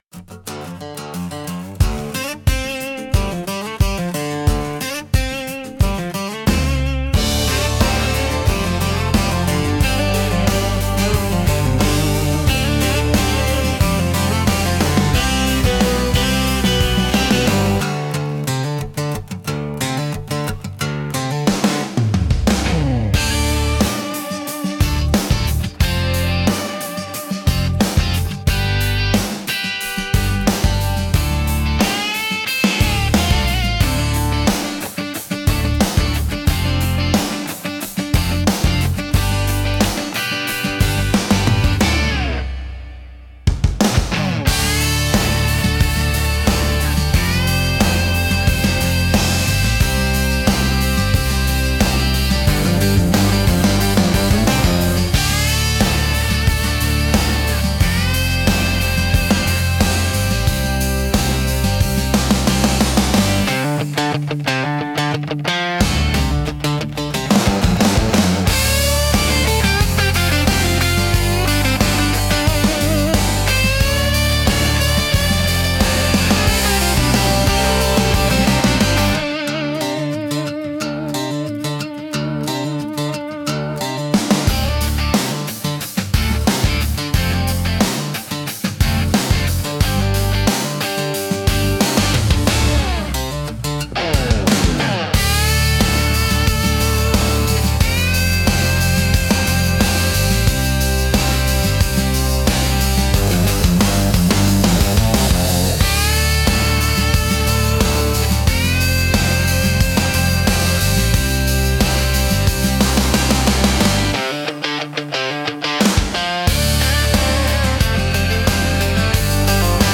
街ジャンルは、ゆったりとしたリズムと明るく親しみやすい曲調が特徴のオリジナルジャンルです。
穏やかでリラックスできる雰囲気を持ち、日常生活や会話シーンによくマッチします。
聴く人に快適で穏やかな気持ちをもたらし、ナチュラルで親近感のある空気感を演出します。